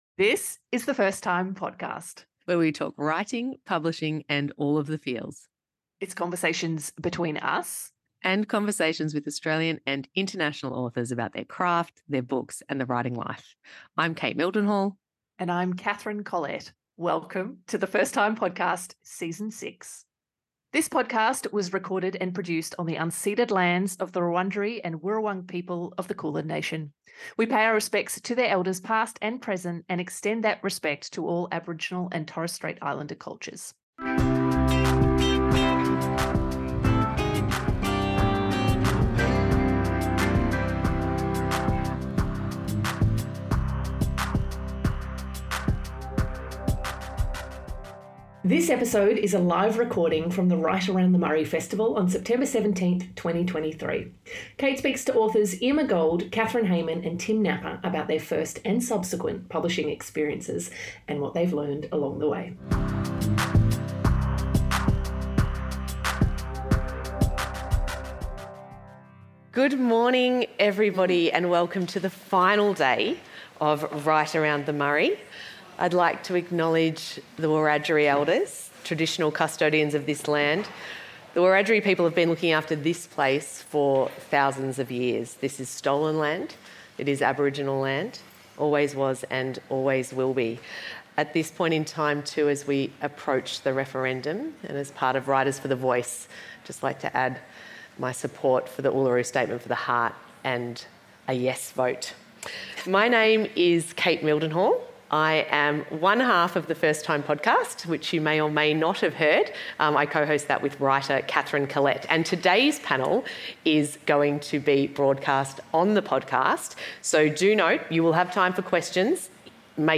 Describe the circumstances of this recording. This episode is a Live recording of the First Time Publishing panel at the Write Around the Murray Festival on September 17, 2023.